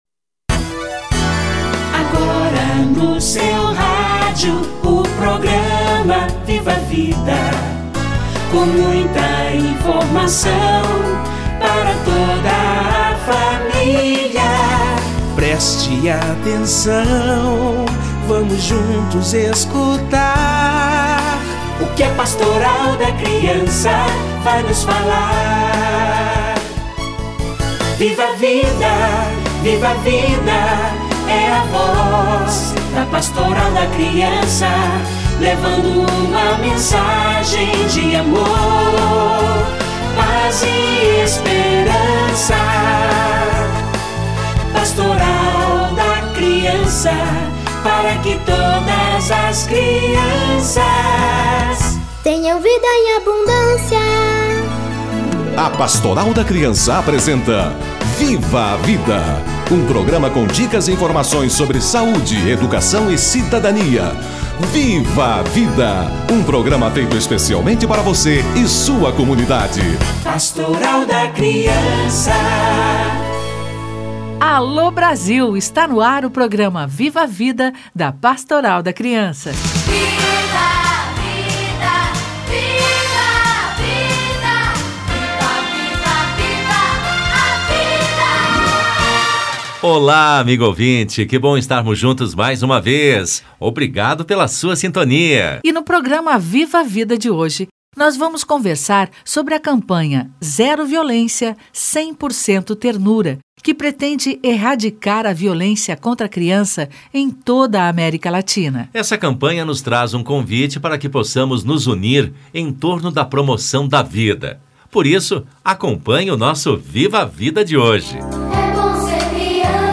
Identificação de sinais de maus tratos ou de violência - Entrevista